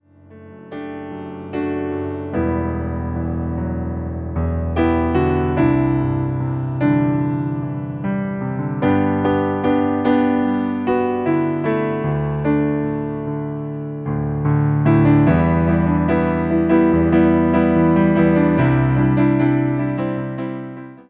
Wersja demonstracyjna:
74 BPM
G – dur